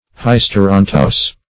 Search Result for " hysteranthous" : The Collaborative International Dictionary of English v.0.48: Hysteranthous \Hys`ter*an"thous\, a. [Gr.
hysteranthous.mp3